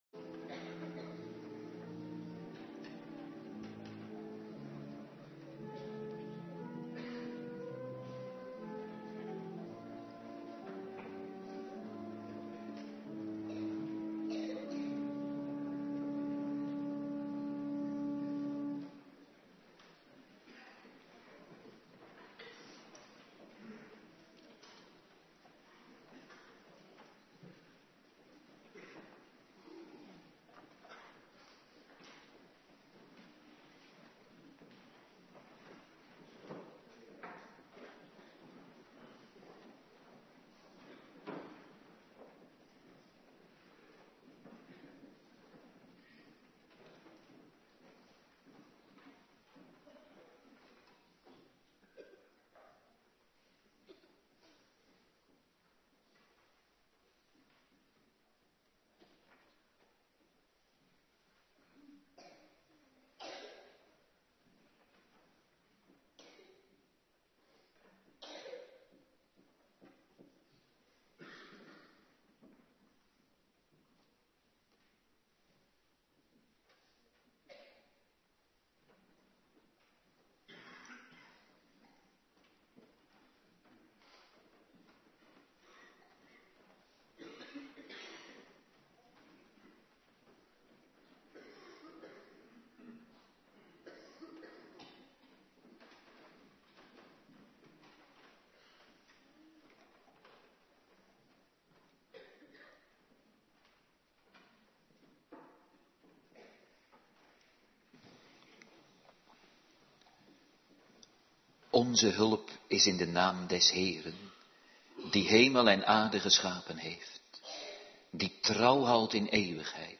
Morgendienst
09:30 t/m 11:00 Locatie: Hervormde Gemeente Waarder Agenda